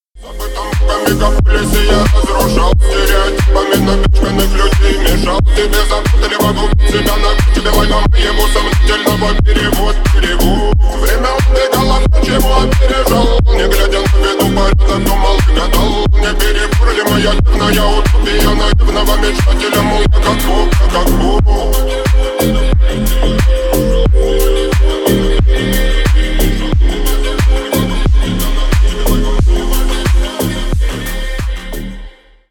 Ремикс
ритмичные